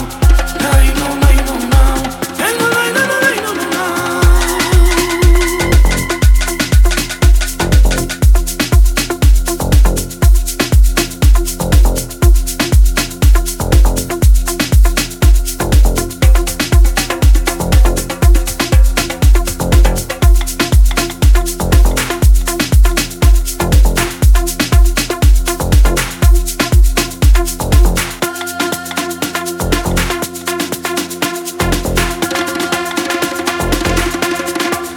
Жанр: Танцевальные / Электроника